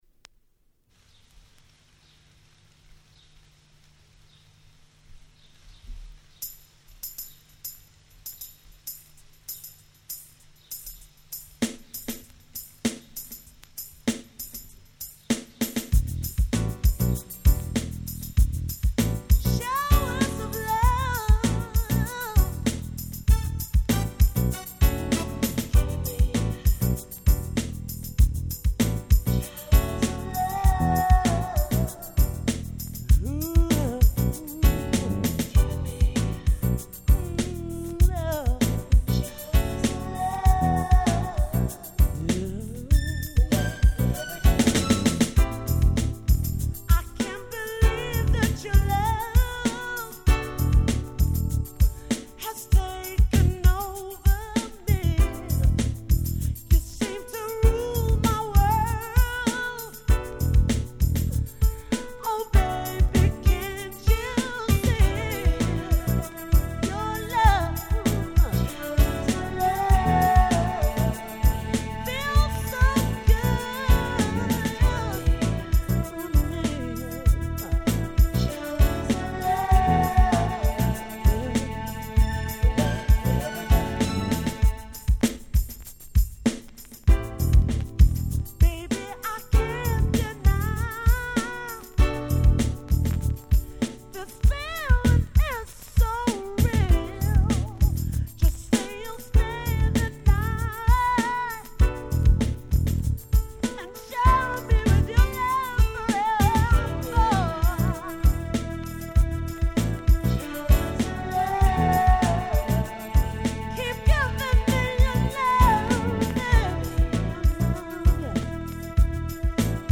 90' Smash Hit UK R&B !!